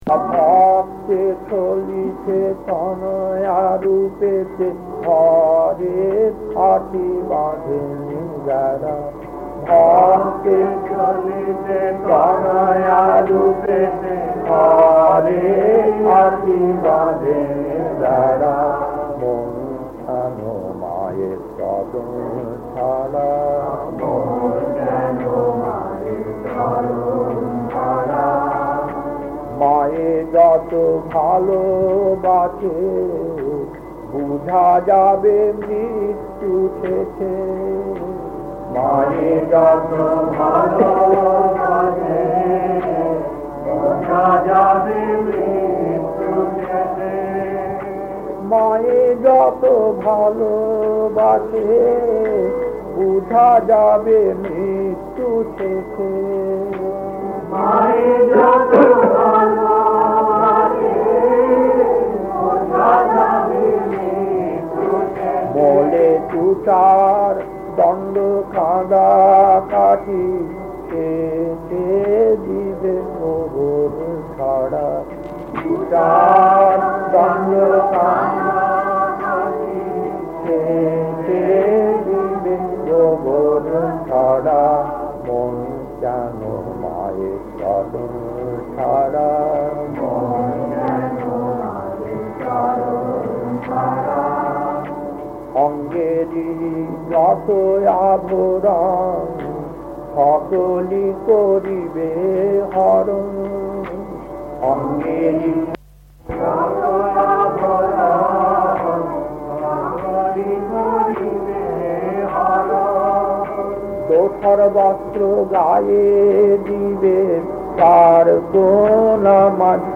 Kirtan A1-2 Rackdale early days 1.